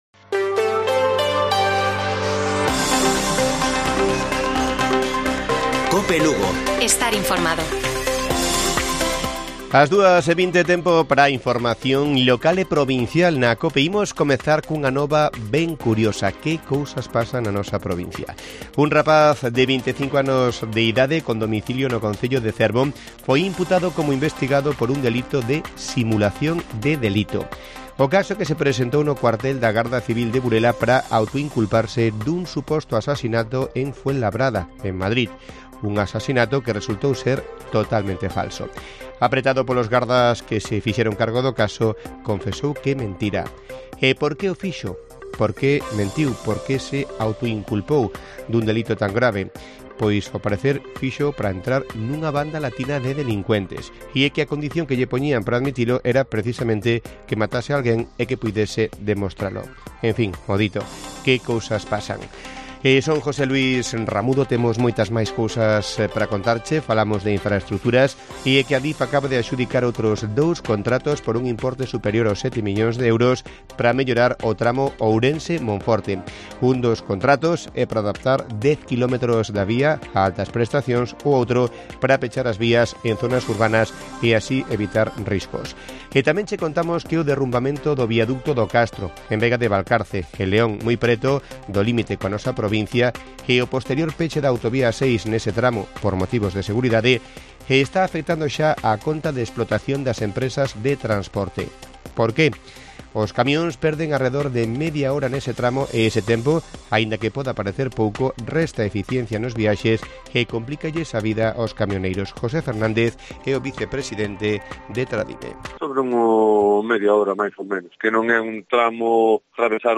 Informativo Mediodía de Cope Lugo. 14 DE JUNIO. 14:20 horas